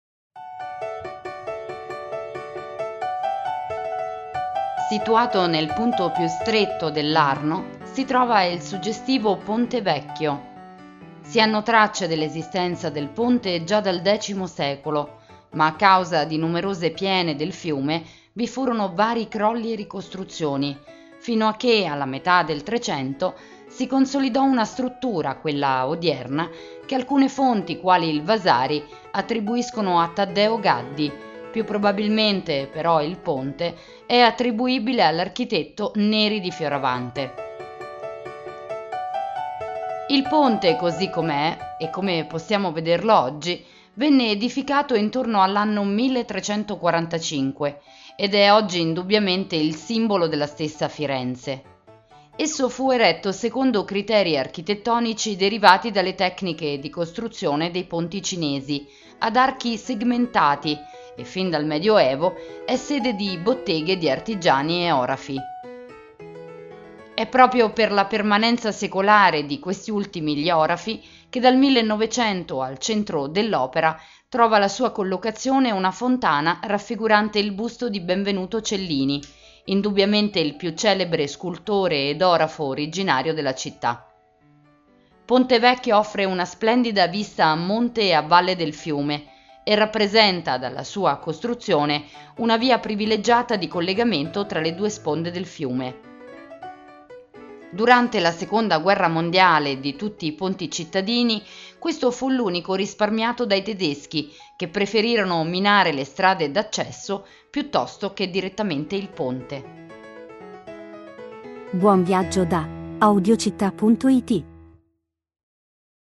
Audioguida Firenze – Ponte Vecchio